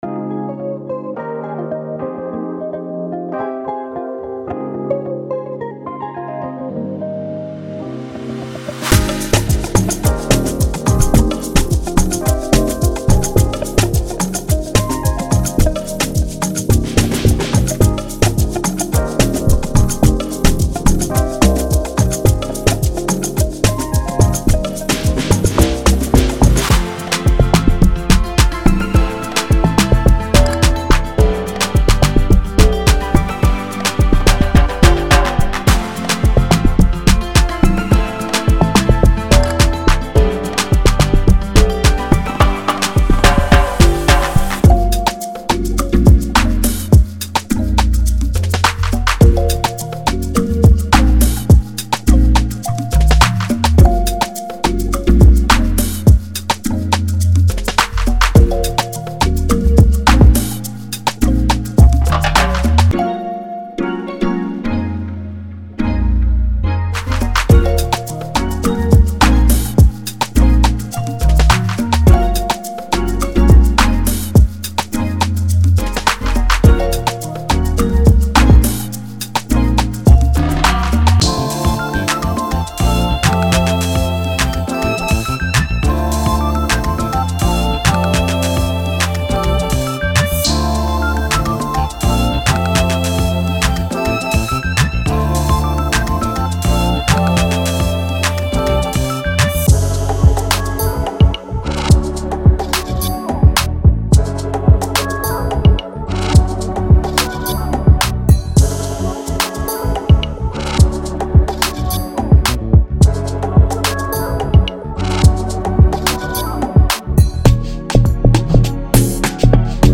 ·     23 Log Drums, Synth Bass & Sub
·     47 Drums & Drum Fills
·     53 Perc Loops